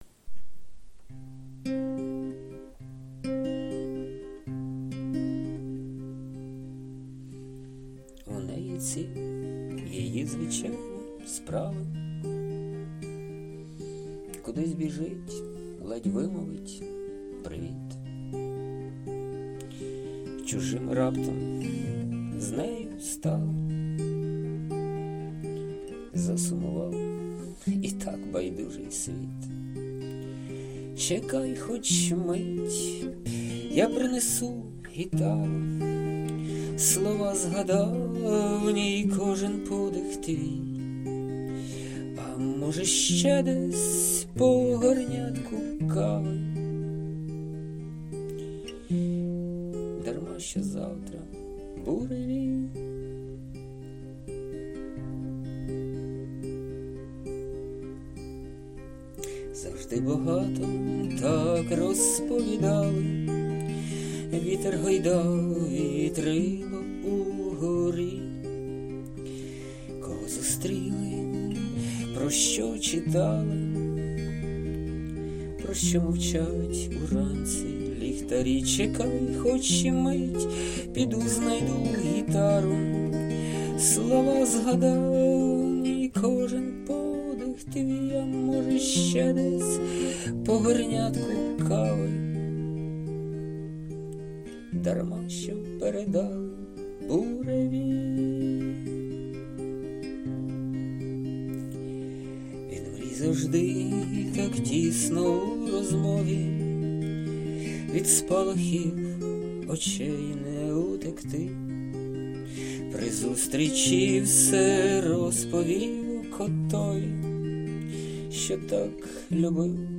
Власне виконання
Запис на телефон
СТИЛЬОВІ ЖАНРИ: Ліричний